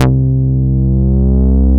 BAS_Prophet5 C3.wav